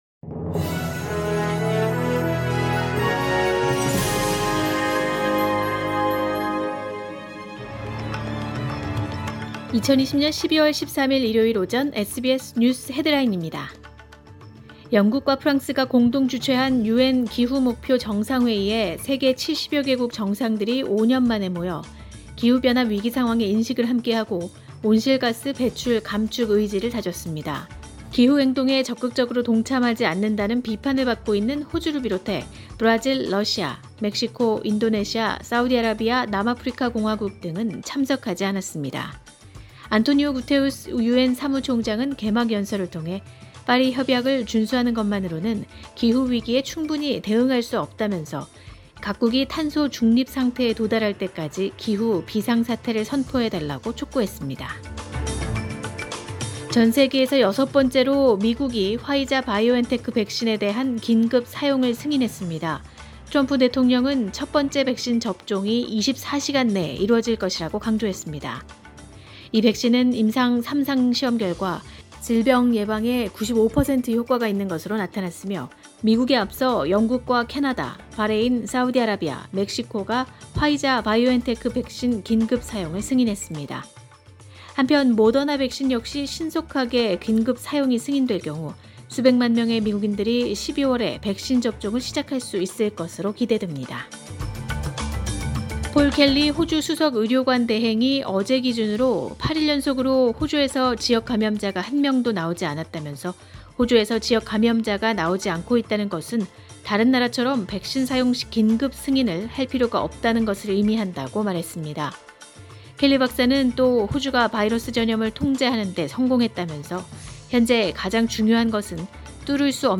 2020년 12월 13일 일요일 오전의 SBS 뉴스 헤드라인입니다.